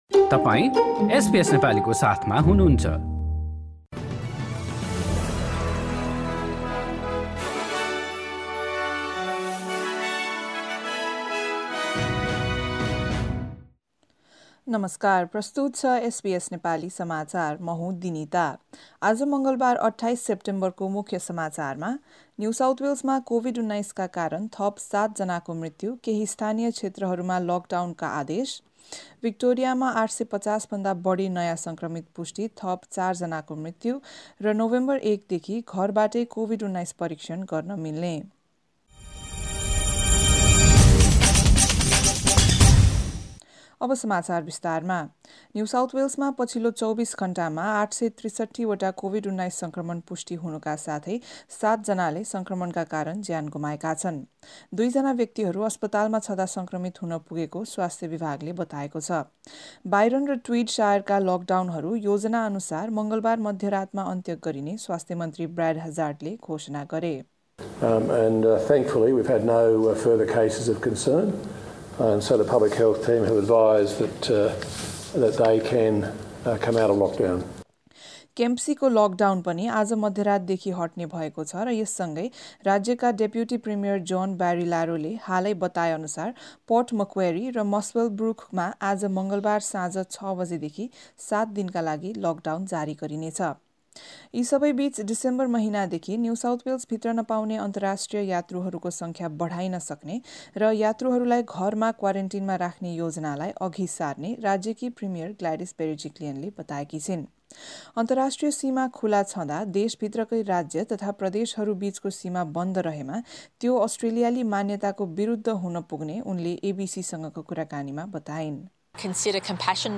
SBS Nepali Australia News: Tuesday 28 September 2021